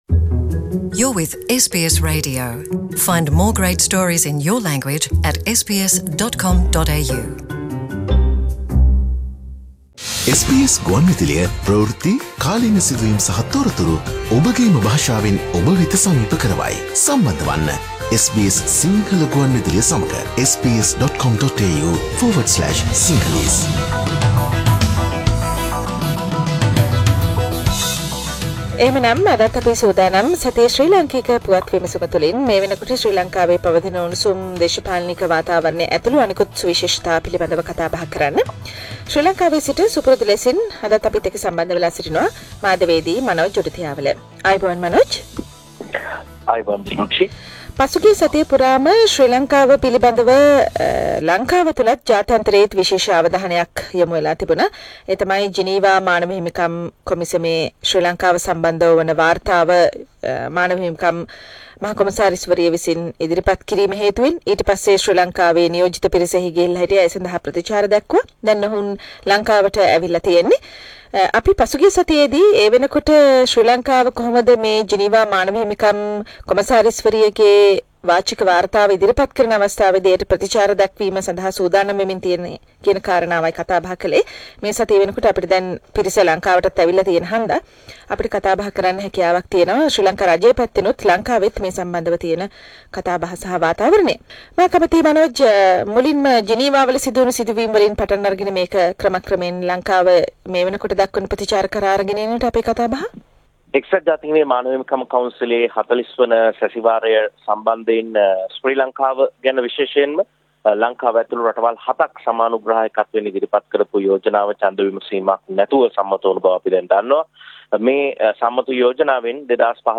Weekly Sri Lankan political wrap Source: SBS Sinhala